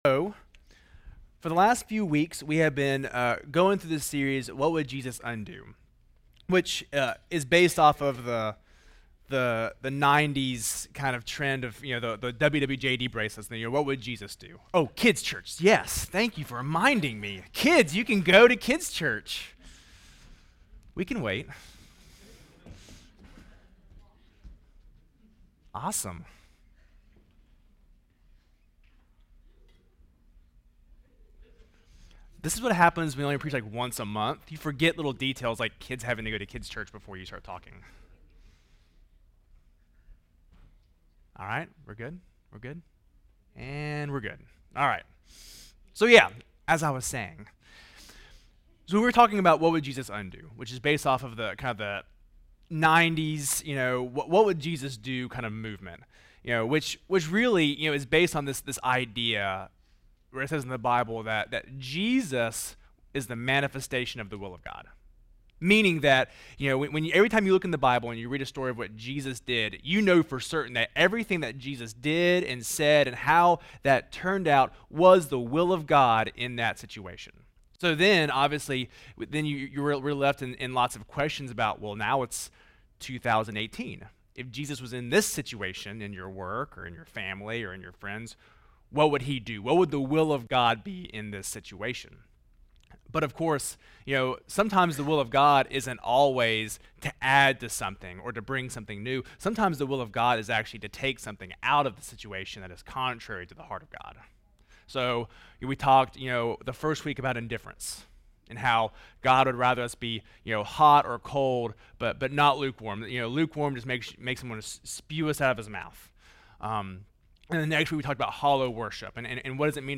Project:Re3 RE3 Sermon Audio